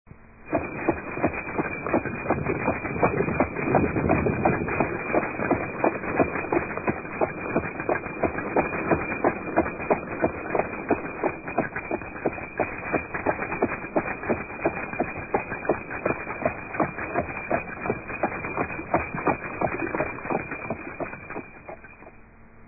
Sound Effects (Instructions: play)